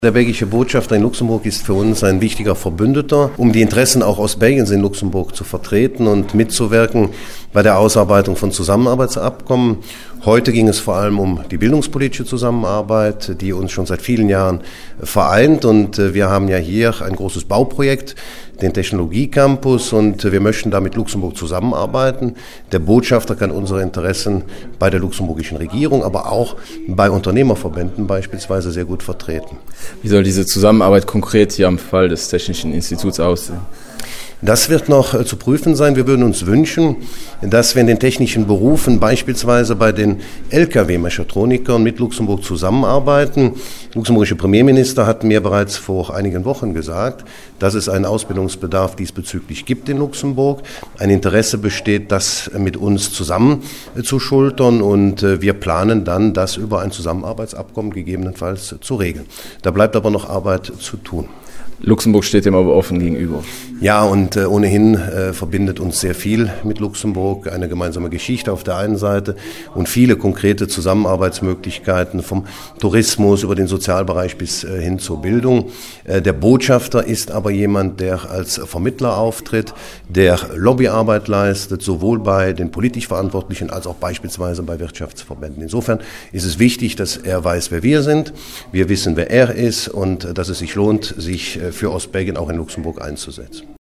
Wie wichtig ist der Botschafter für Ostbelgien? Dazu MP Oliver Paasch: